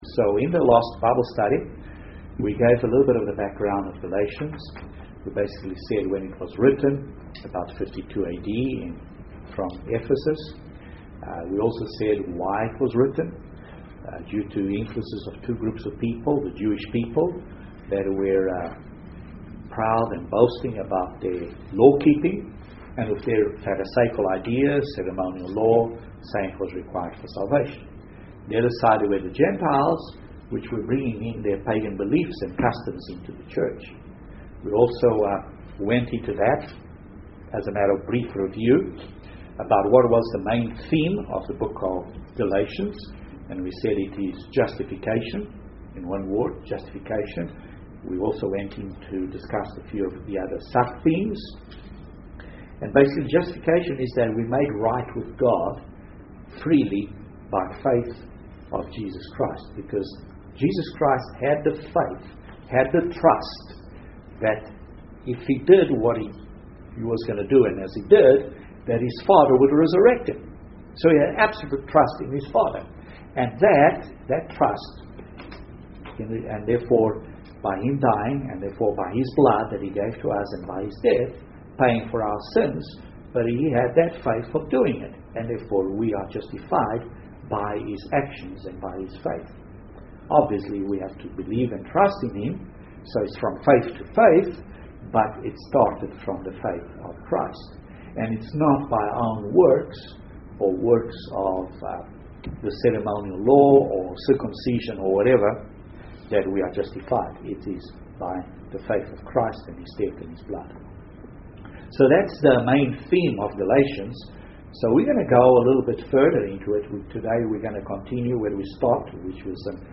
* Paul's credentials UCG Sermon Transcript This transcript was generated by AI and may contain errors.